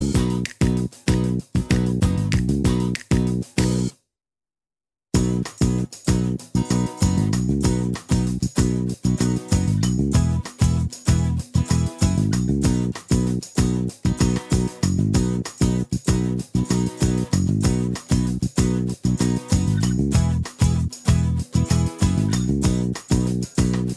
With Organ Intro Pop (1980s) 3:00 Buy £1.50